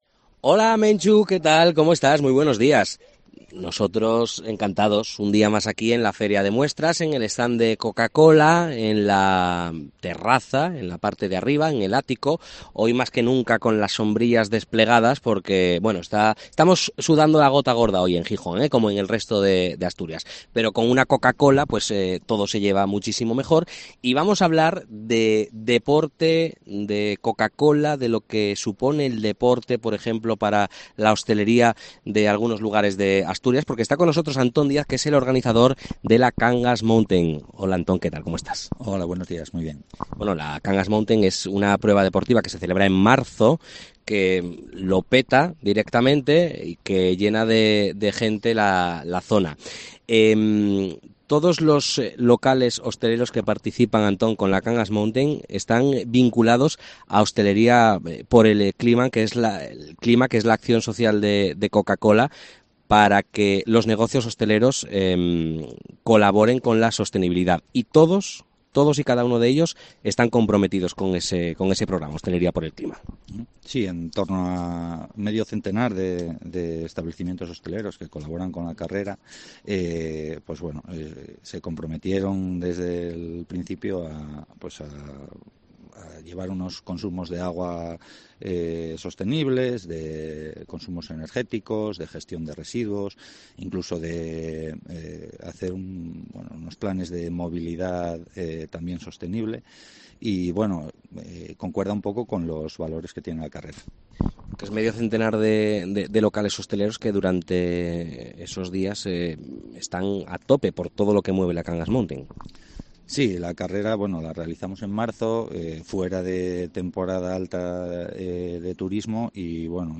La Feria de Muestras también es deporte.